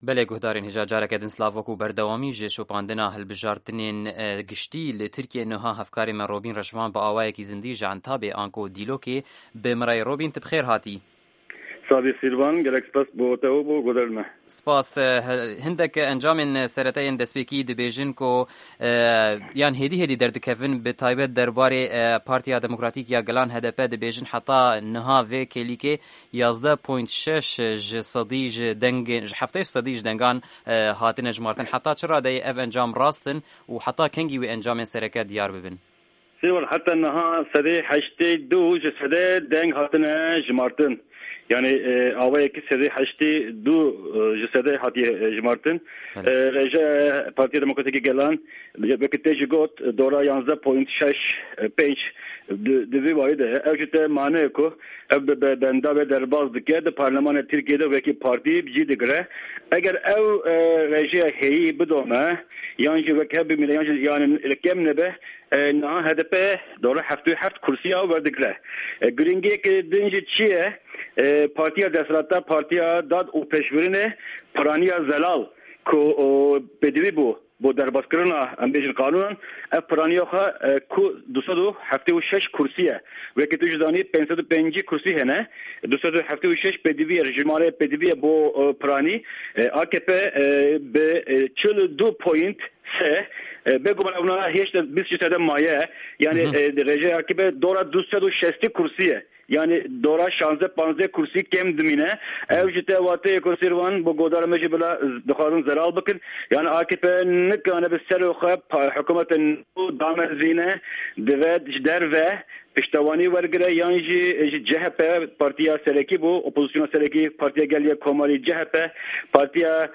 Hevpeyvîn ligel Nûçegihanên Me li Tirkiyê